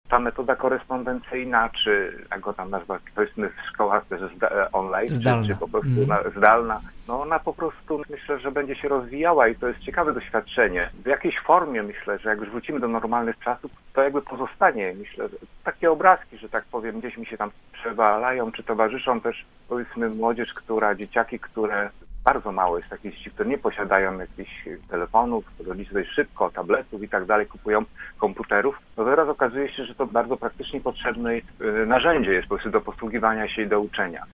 Zdalne nauczanie po epidemii koronawirusa będzie się rozwijało – uważa Marek Budniak. Radny Prawa i Sprawiedliwości był gościem Rozmowy Punkt 9.